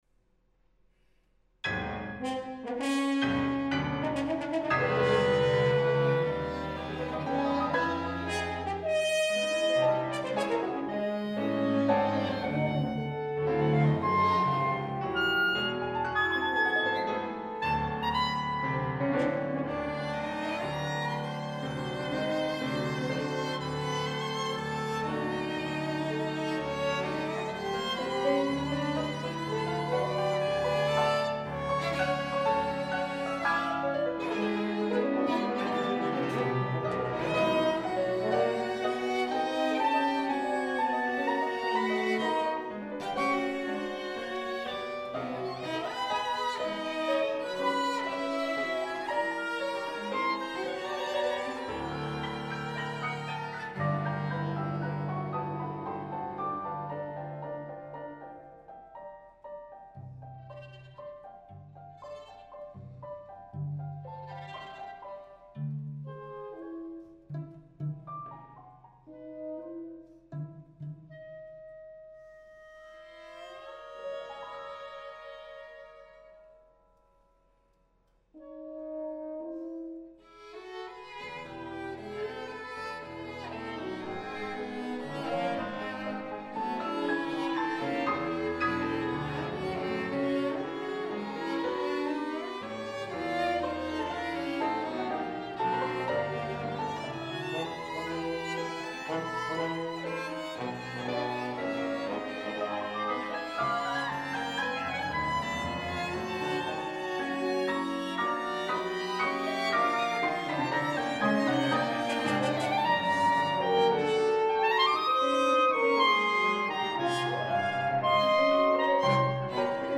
Reid Concert Hall, Edinburgh University, 23 April 2011